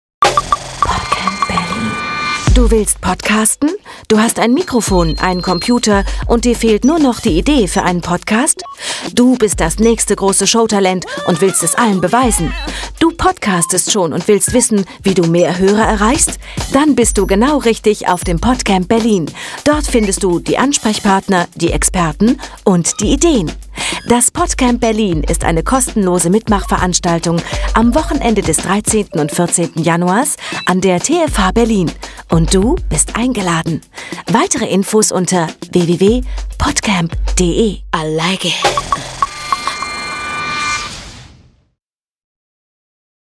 Die Sound-Trailer fuer das PodCamp sind fertig.
Spot 1 – Spassige Variante
Vielen Dank an unseren Sponsor dynamicaudio für das grossartige Sounddesign in den sehr gelungenen Spots.
podcamp_spot_1_fun.mp3